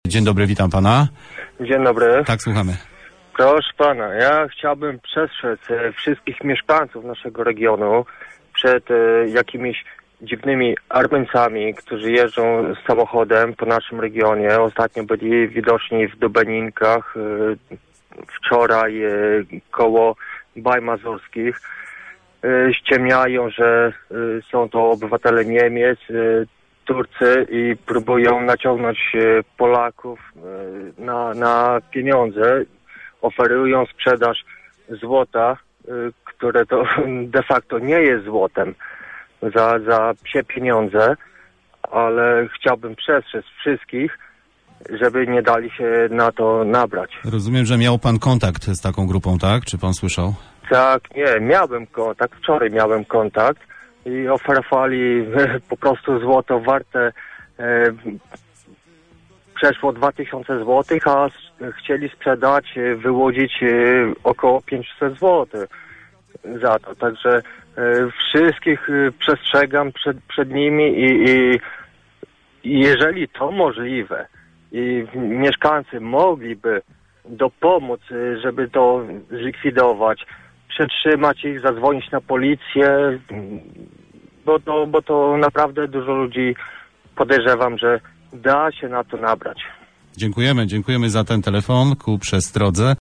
fragment audycji w Radiu 5, ostrzeżenie słuchacza